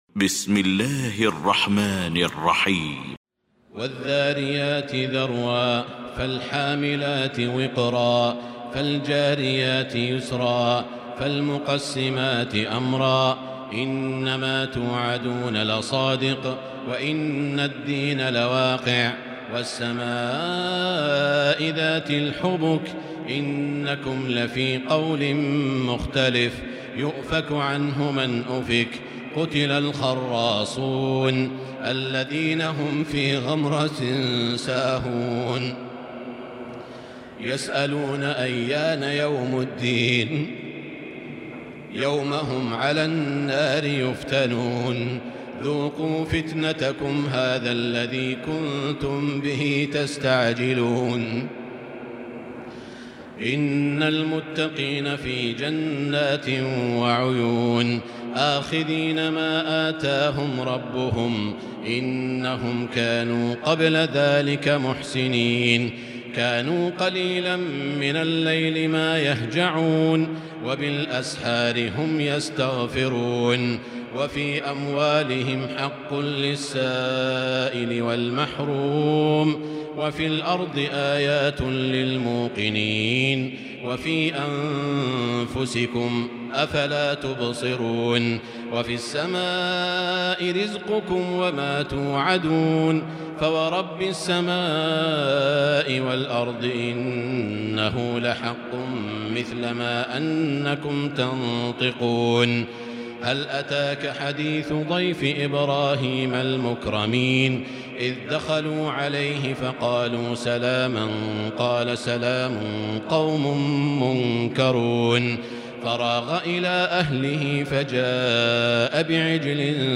المكان: المسجد الحرام الشيخ: سعود الشريم سعود الشريم معالي الشيخ أ.د. عبدالرحمن بن عبدالعزيز السديس الذاريات